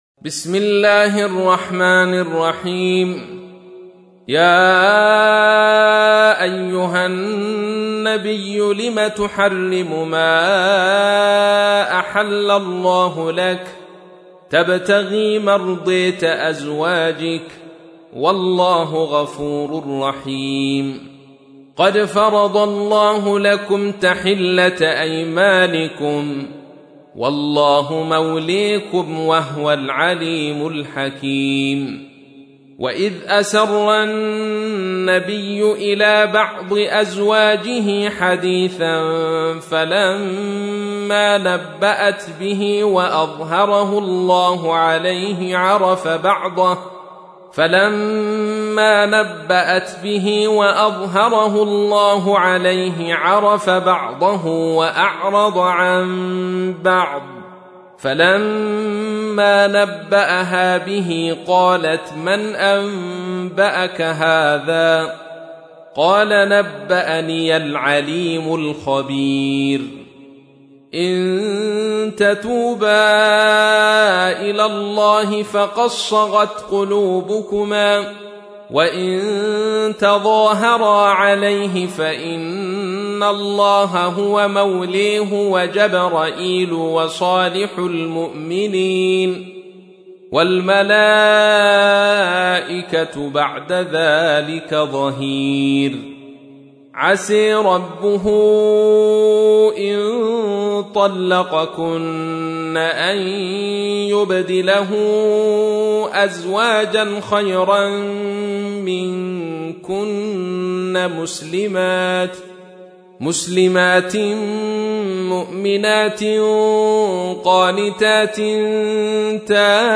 تحميل : 66. سورة التحريم / القارئ عبد الرشيد صوفي / القرآن الكريم / موقع يا حسين